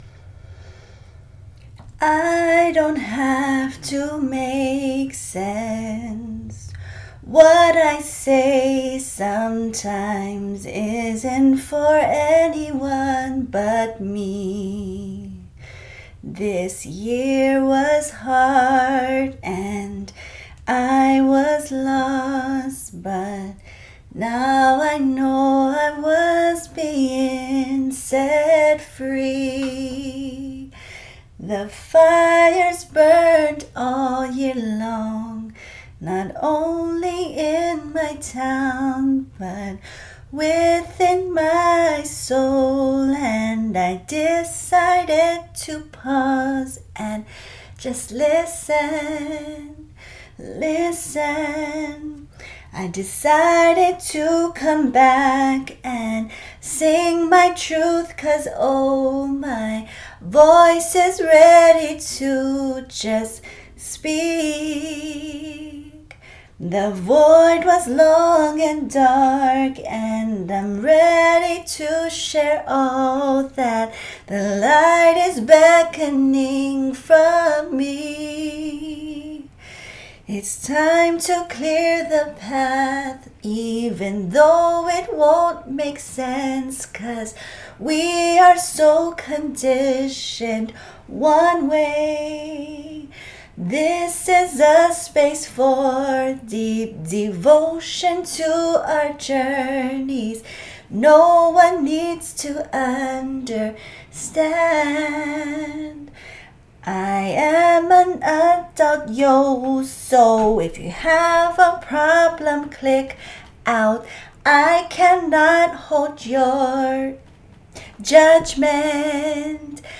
Opening up with a raw sound, and closing with a prayer, from My heart to YOURS, over Yours.